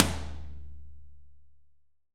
R.AMBTOML2.wav